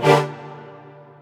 stringsfx1_3.ogg